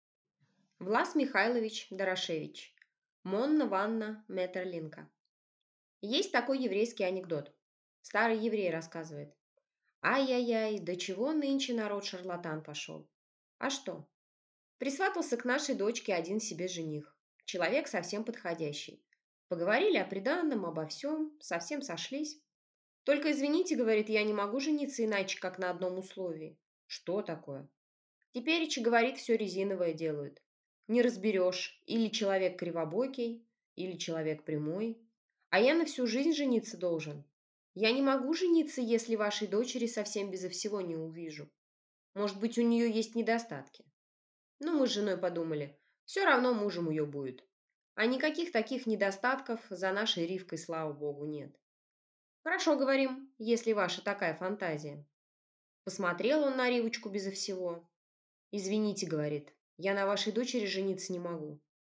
Аудиокнига «Монна-Ванна» Метерлинка | Библиотека аудиокниг
Прослушать и бесплатно скачать фрагмент аудиокниги